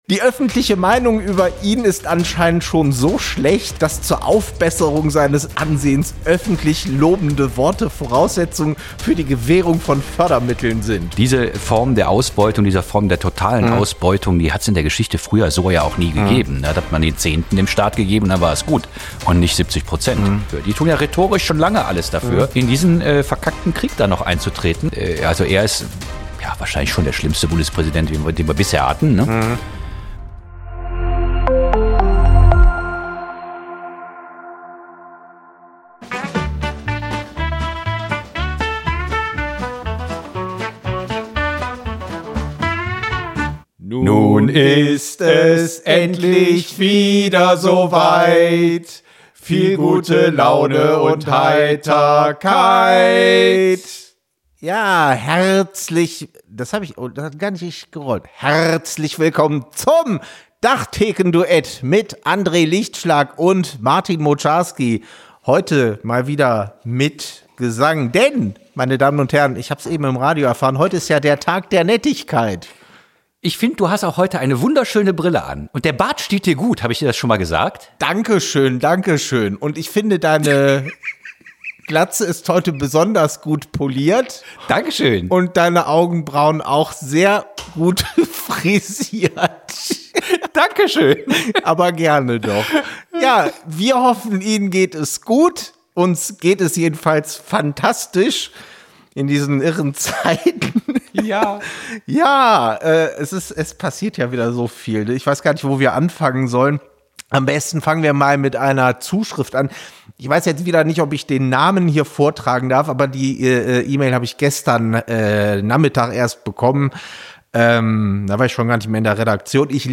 Kleine Redaktionskonferenz am Tresen
Wie immer werden in dieser Reihe an der ef-Theke spontan aktuelle Entwicklungen und brennende Themen beleuchtet.